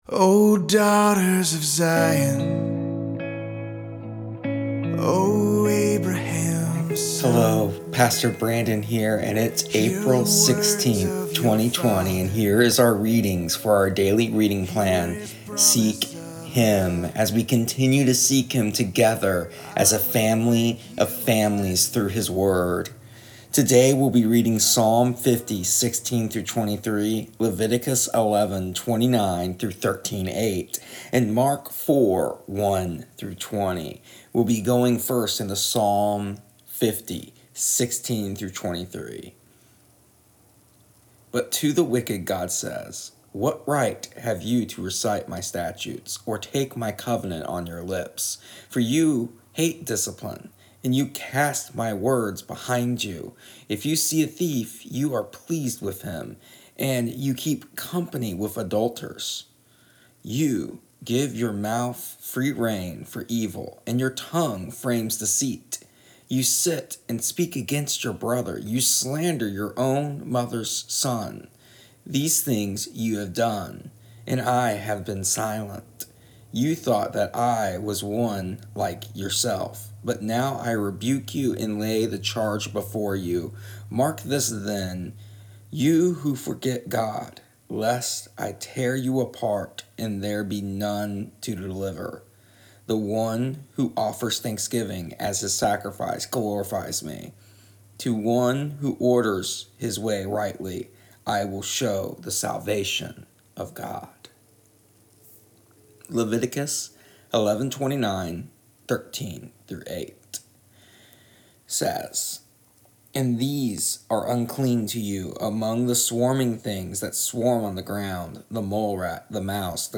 Here is our daily reading plan in audio.